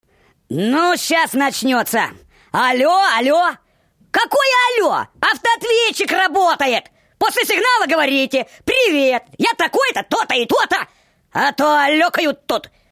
** Приветствующие сообщения для автоответчика **